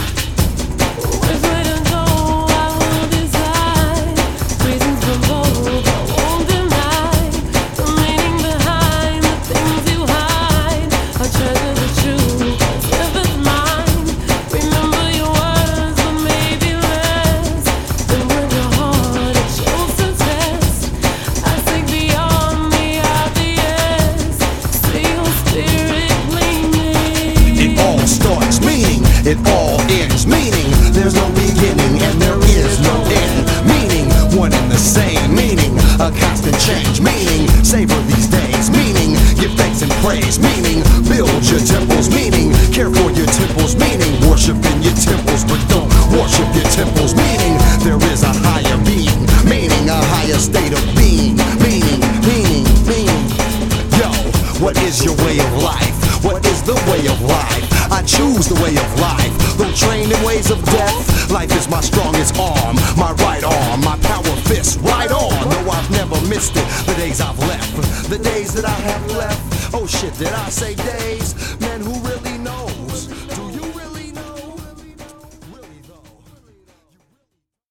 rhymes
beats